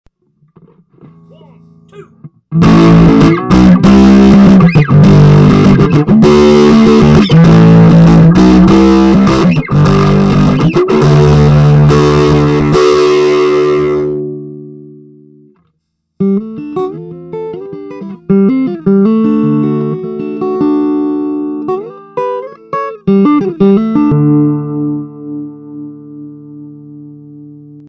I used my PC headset mike direct into a Toshiba laptop.
The others really show how varying touch moved the amp from clean to grind and back. Cuts 1-3 are pure, raw cuts, and are a bit misleading.
Starts hot, then use soft touch for clean (no volume change on guitar)
grind2soft_touch.mp3